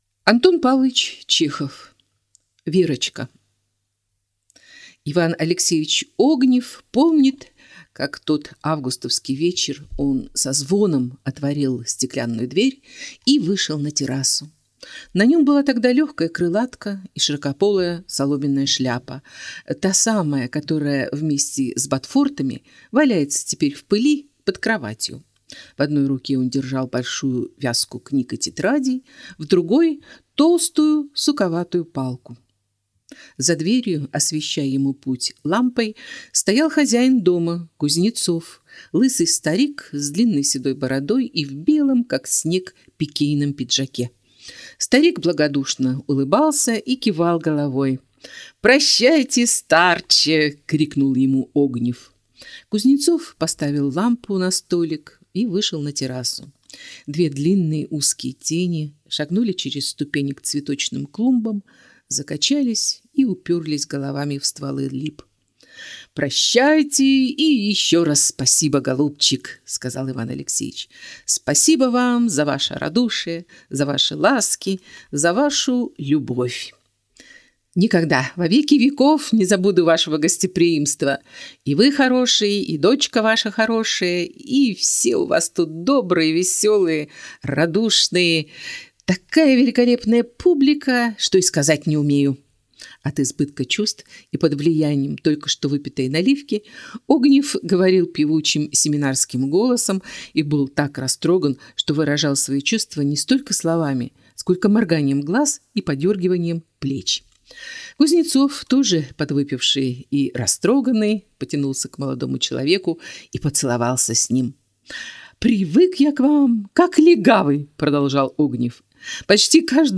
Аудиокнига Верочка | Библиотека аудиокниг